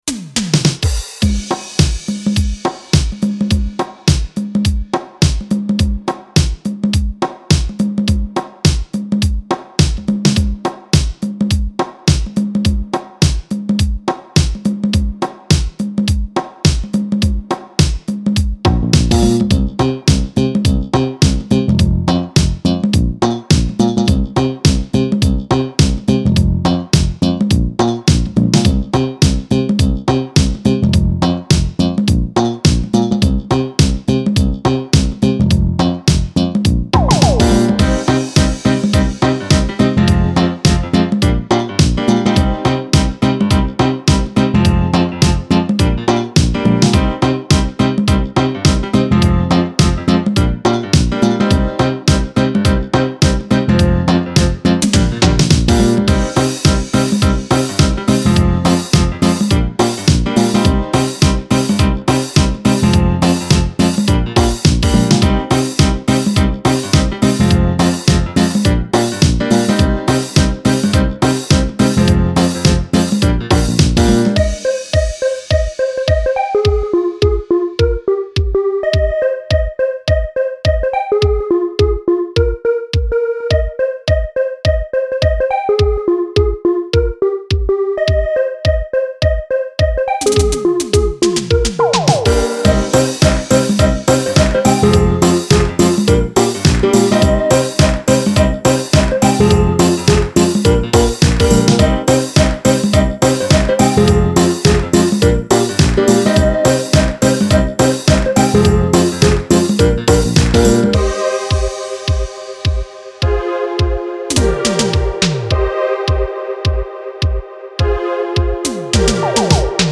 Genre: Deep House.